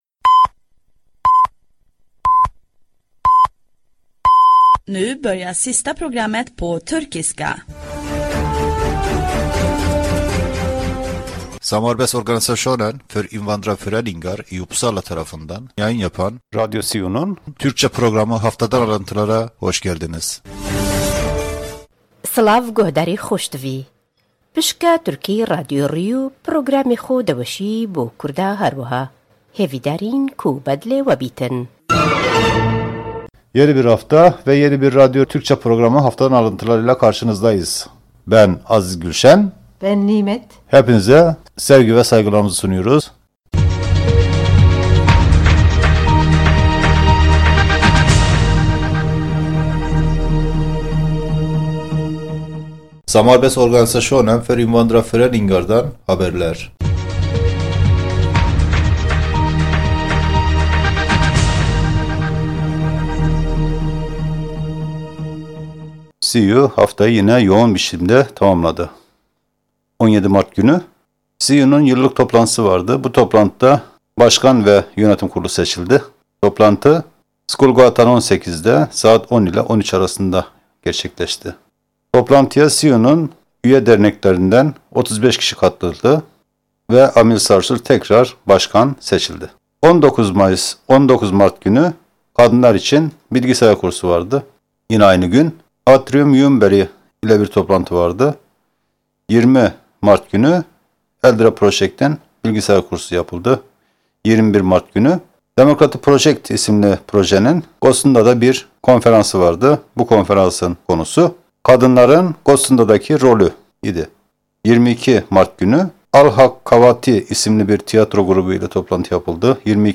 Haftadan alıntılar programında, bir onceki haftanın gelişmeleri haber programında dinleyicilere iletiliyor. Ayrica müzik kısmında, Türkçe müzik parçalarınada yer veriliyor.